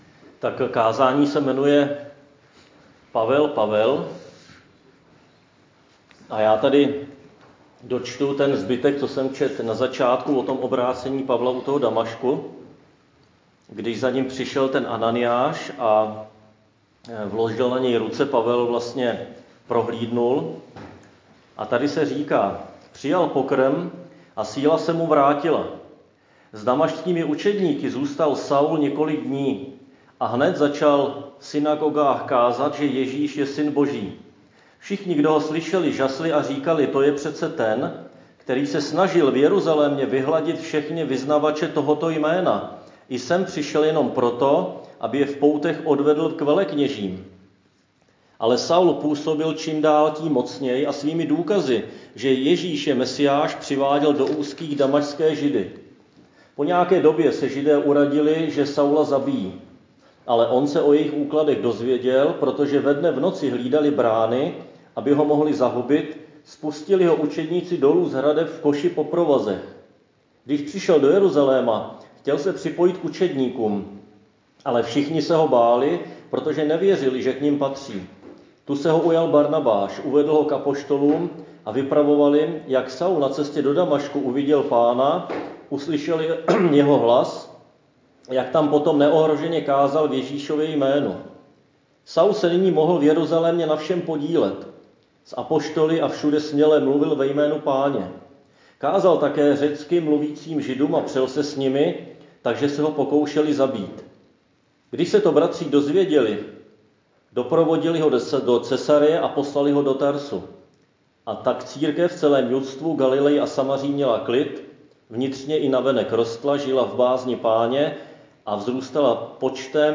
Křesťanské společenství Jičín - Kázání 14.3.2021